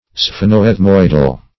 Sphenoethmoidal \Sphe"no*eth*moid`al\